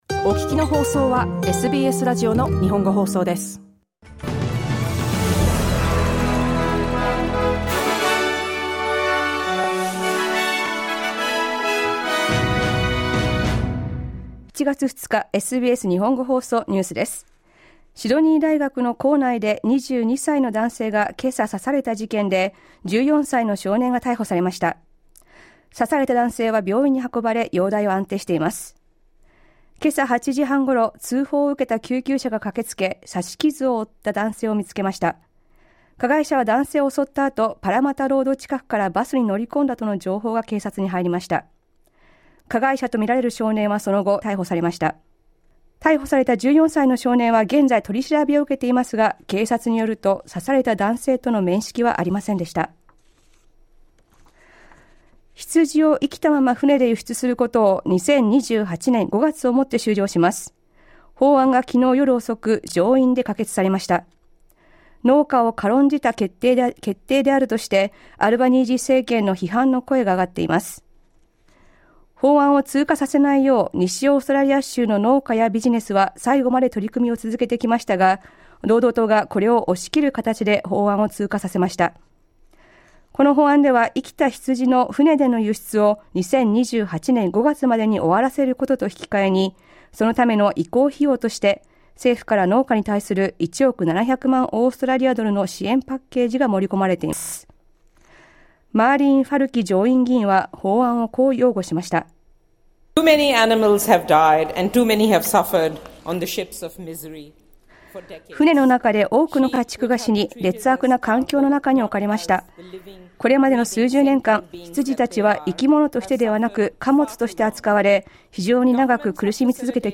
午後１時から放送されたラジオ番組のニュース部分をお届けします。